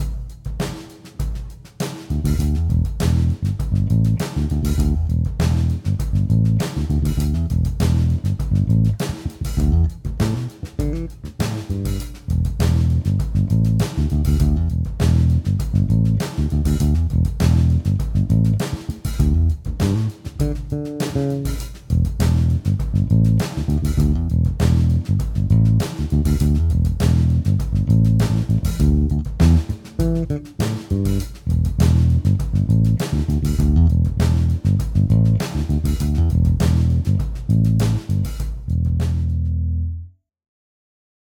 Edit: nahráváno přes VSTčko Ampeg B15R
Suhr Classic-J, struny víceméně nové, ale ocel. 4. Stingray Special US - nové struny.